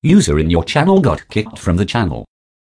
user.left.kicked.channel.wav